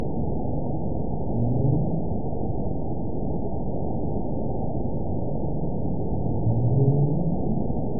event 914836 date 11/02/22 time 22:22:15 GMT (2 years, 6 months ago) score 8.01 location INACTIVE detected by nrw target species NRW annotations +NRW Spectrogram: Frequency (kHz) vs. Time (s) audio not available .wav